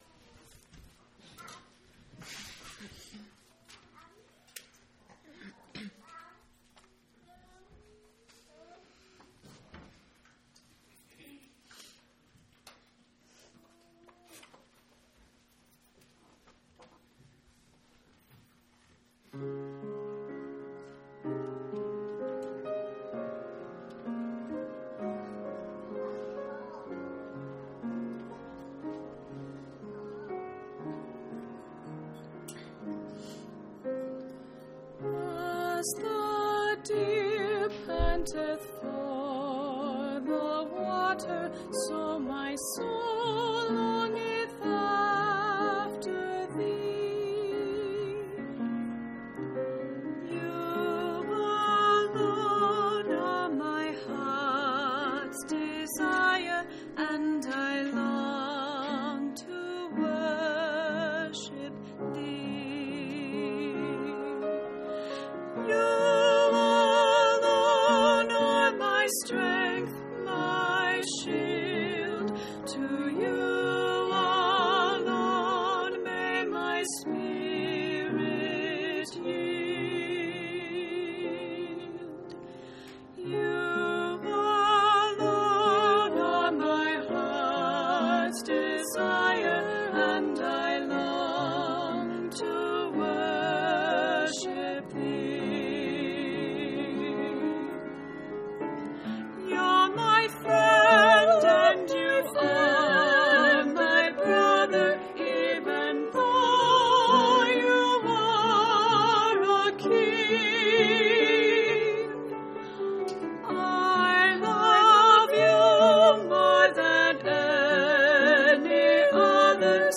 audio-sermons